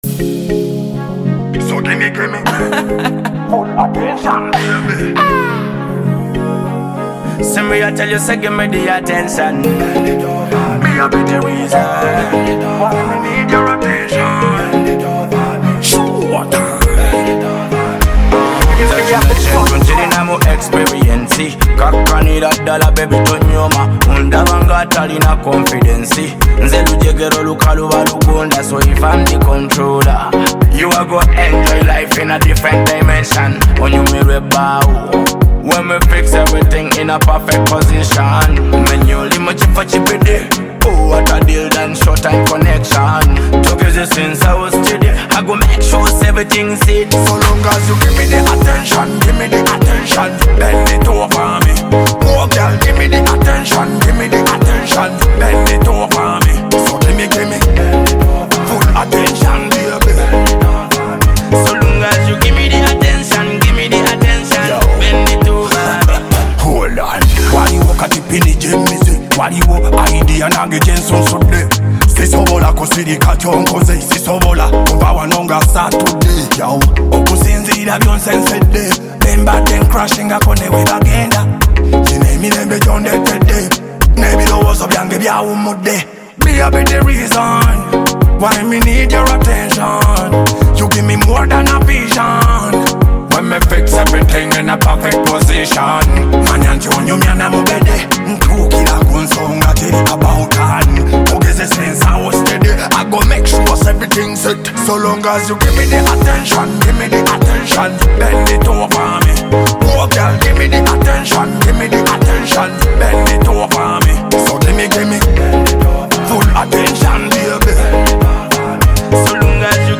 Luga flow And Afro Beat singers